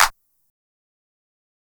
Clap (Sneaky).wav